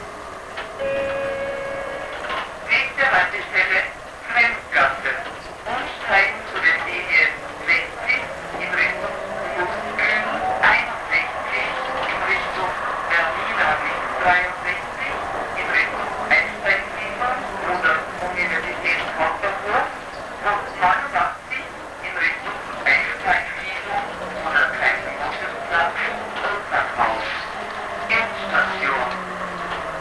Haltestellenansagen